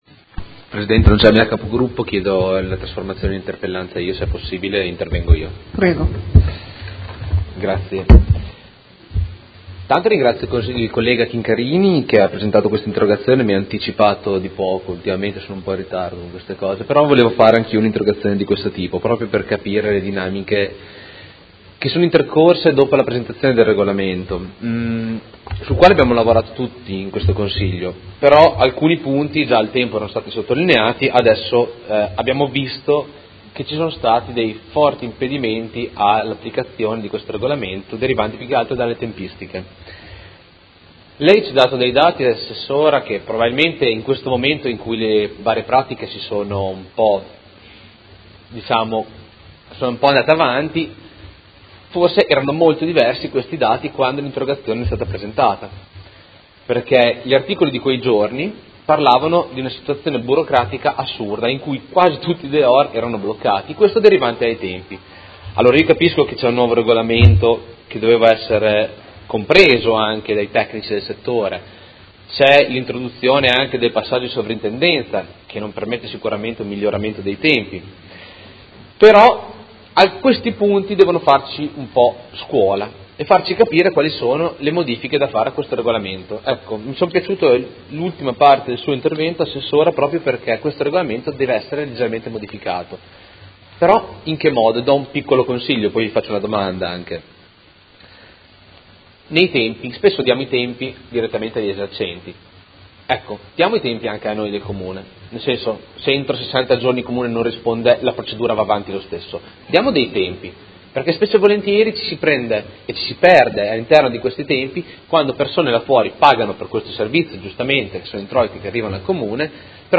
Seduta del 8/6/2017 Interrogazione del Gruppo Per Me Modena avente per oggetto: Quali difficoltà ha trovato l’applicazione del nuovo Regolamento dei dehors? Chiede la trasformazione in interpellanza.